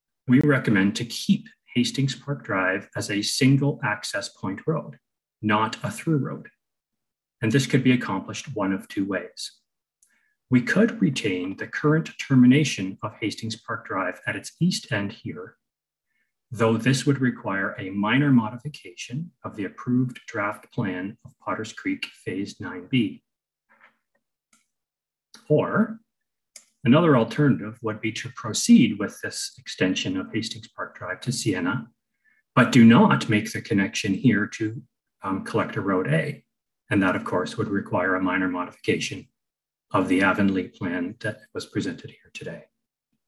At Tuesday’s Planning Advisory Committee meeting a public meeting was held regarding the proposed Village of Avonlea subdivision.
Another man, also a resident of the Hastings Park subdivision, raised concerns about the roads in the area.